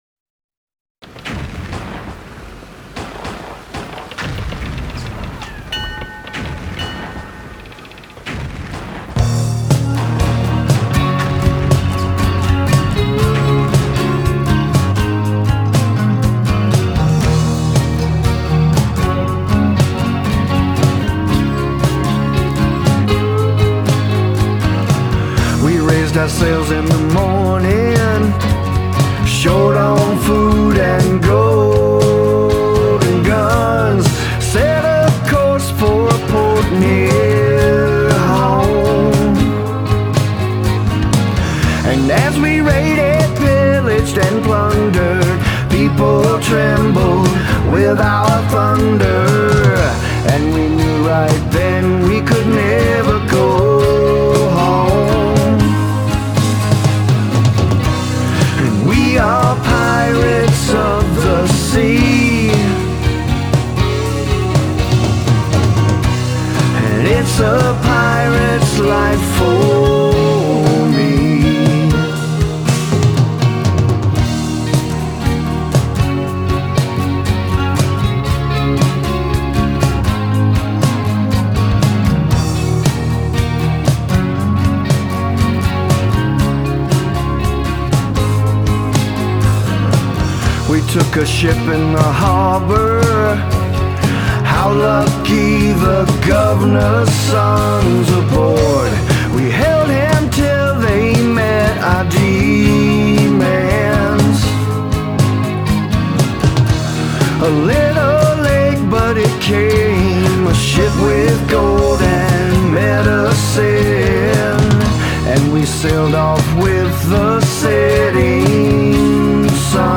Genre: Folk Rock, Blues, Americana